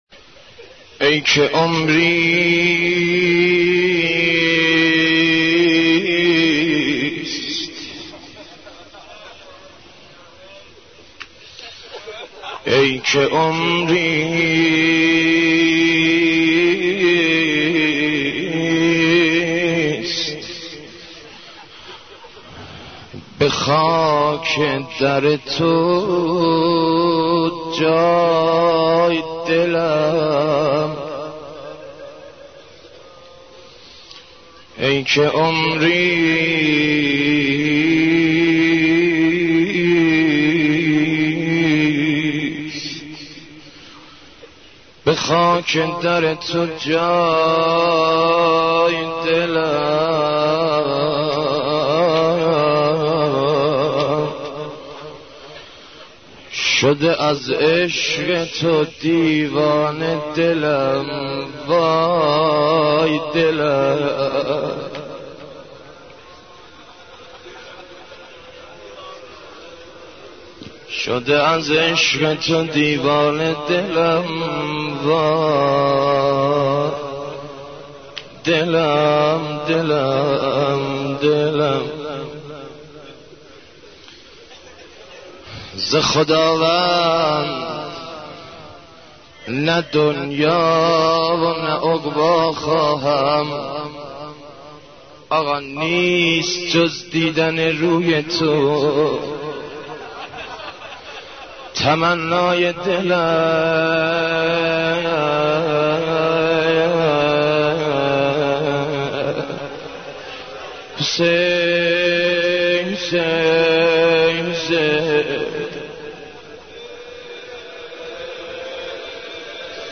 مداحی امام حسین ع 10